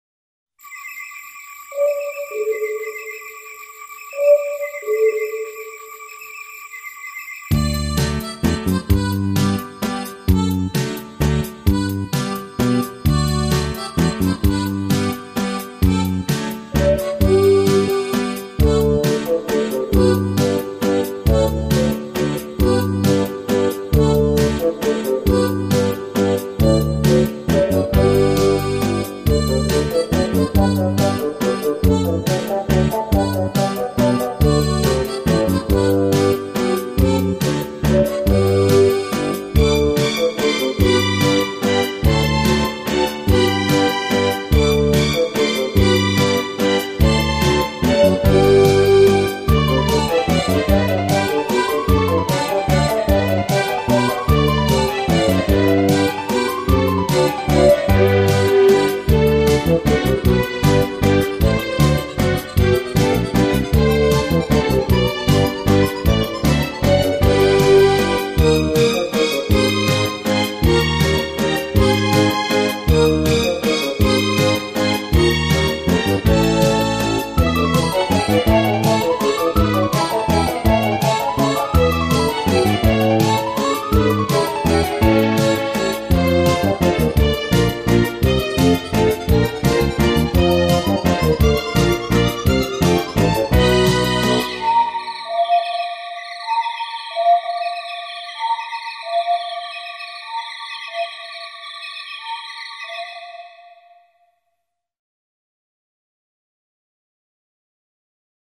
танцевальная песня
Народные мелодии хорошо подходят для активного слушания.